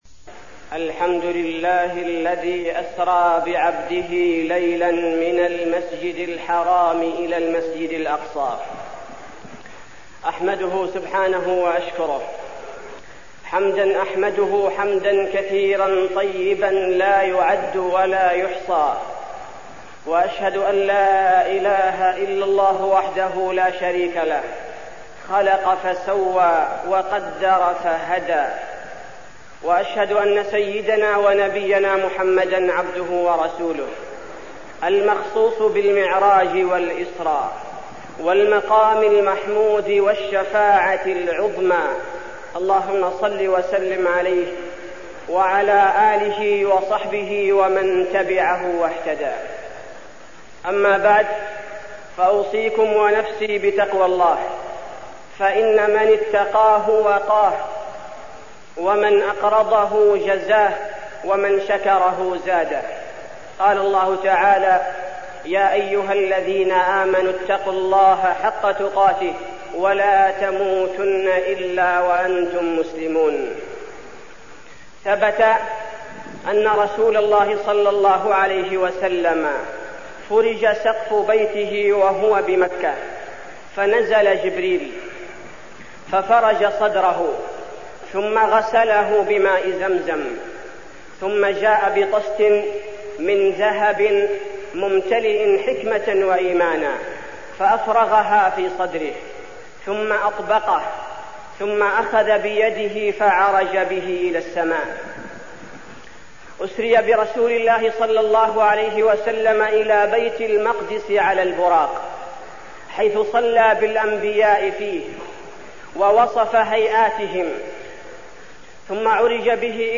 تاريخ النشر ٧ رجب ١٤١٨ هـ المكان: المسجد النبوي الشيخ: فضيلة الشيخ عبدالباري الثبيتي فضيلة الشيخ عبدالباري الثبيتي الإسراء والمعراج